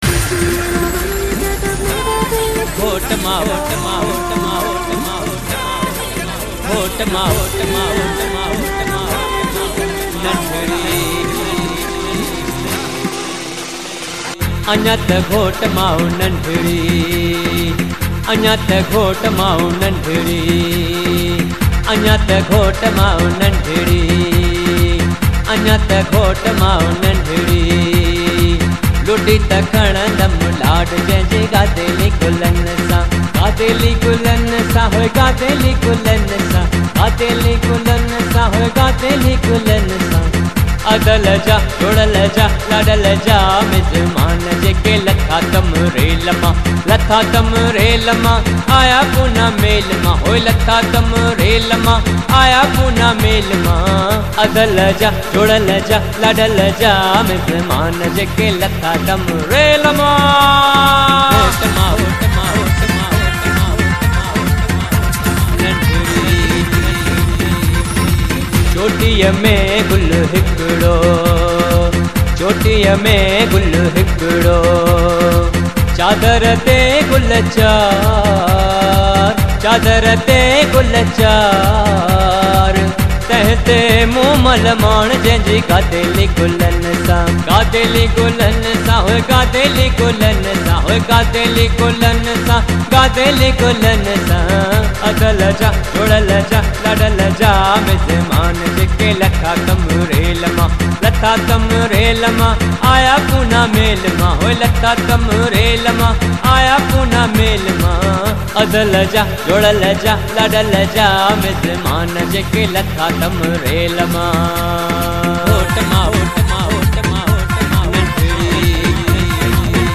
Sindhi Songs for Lada (Ladies Sangeet)